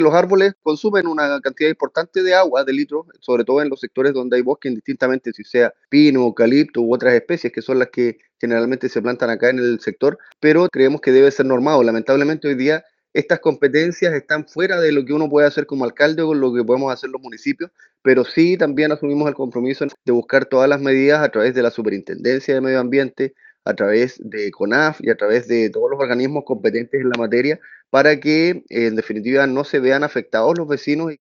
Sobre el tema, el alcalde de Río Negro, Sebastián Cruzat, sostuvo que el municipio enfrenta atribuciones limitadas para intervenir en el conflicto, pese a los efectos que las actividades forestales generan sobre la disponibilidad de agua.
alcalde-rio-negro-forestal-2.mp3